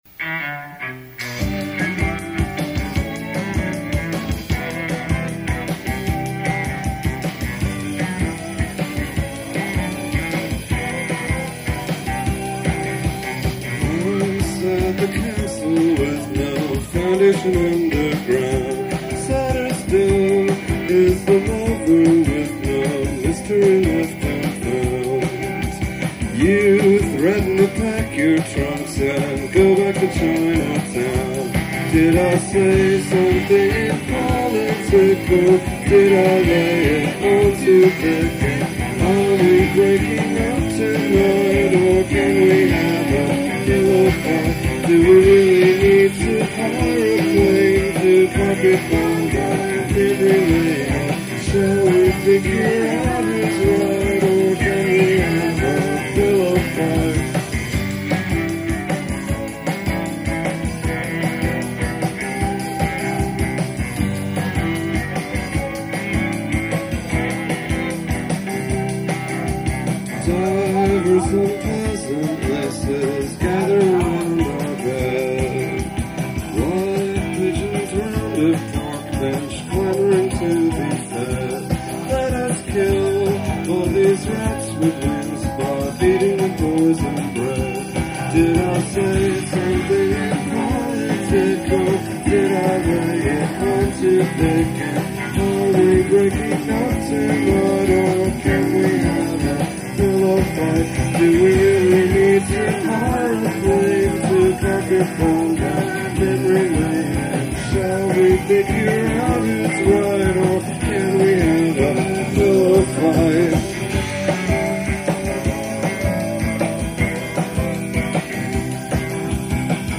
This song was recorded live at The Mercury Lounge in 1995.